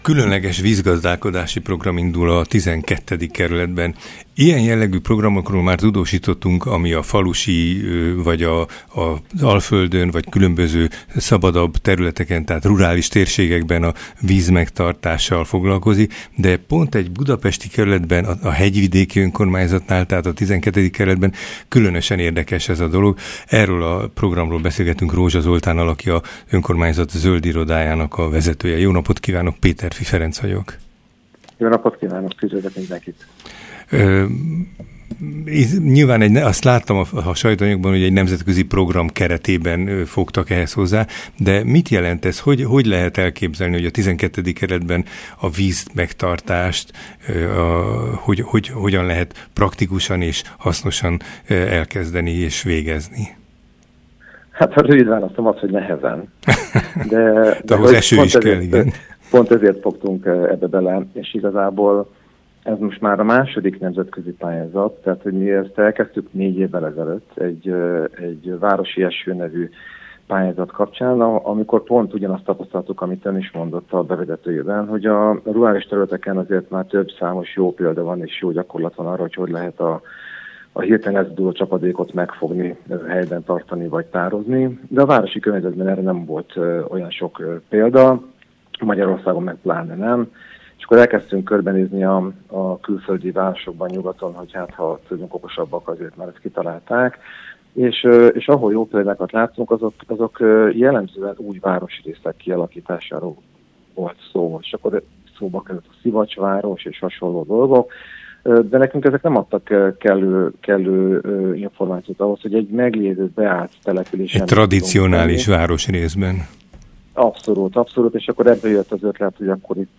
Interjú a Civil Rádióban